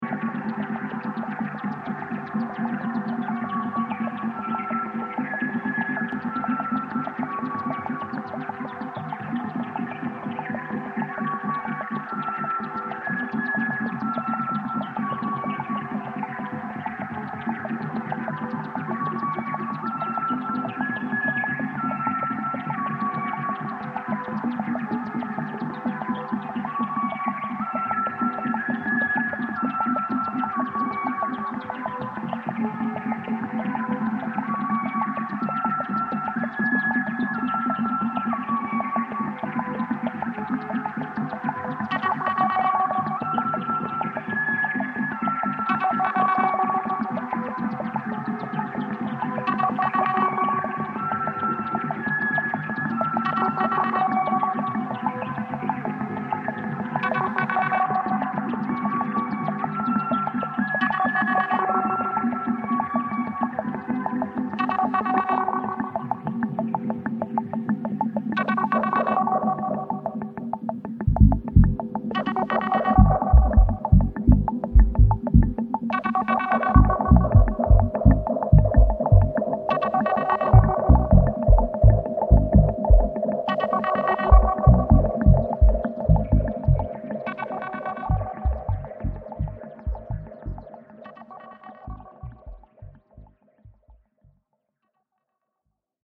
Warm and dubby organic sounds
House